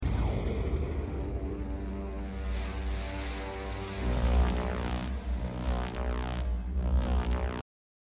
The Black Hole FX - Cygnus reactors
The_Black_Hole_FX_-_Cygnus_reactors.mp3